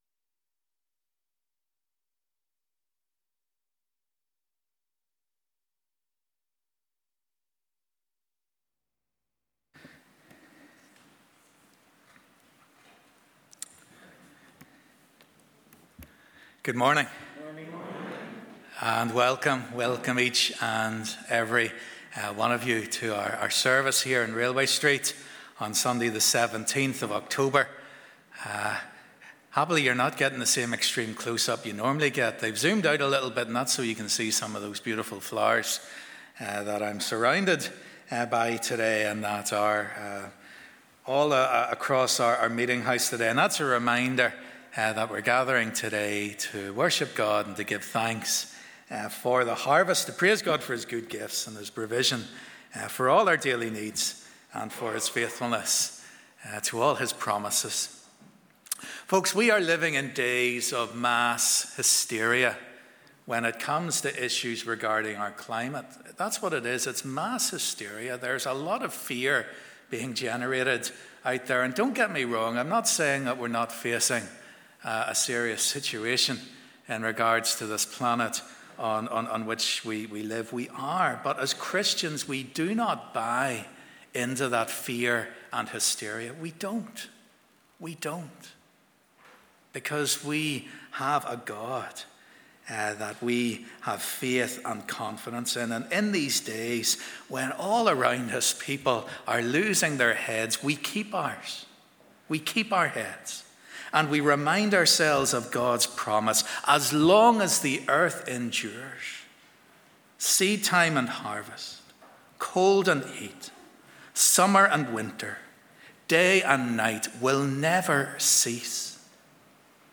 Harvest Thanksgiving Service: 'Share The Load This Harvest'